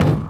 Teck-perc (pum pum).wav